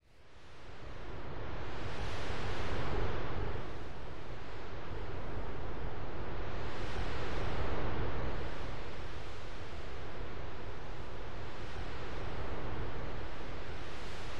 Storm.wav